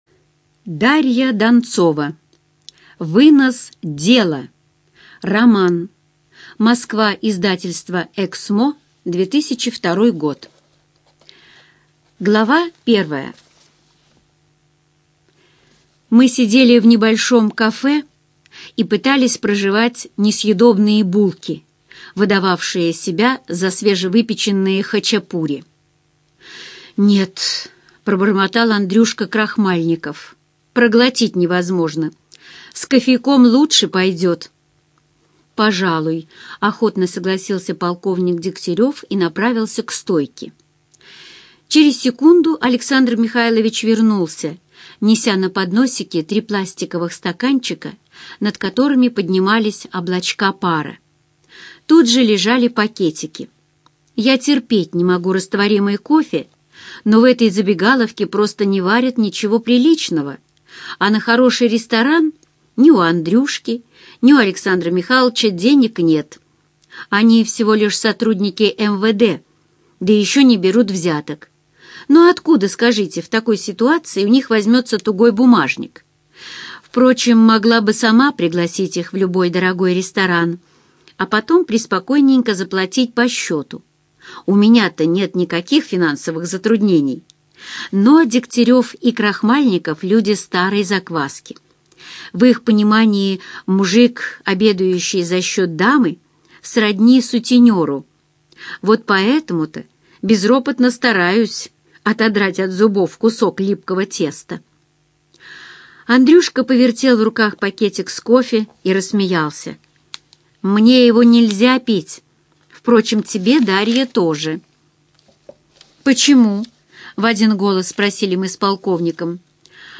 Аудиокнига Вынос дела - купить, скачать и слушать онлайн | КнигоПоиск